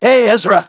synthetic-wakewords
ovos-tts-plugin-deepponies_Michael_en.wav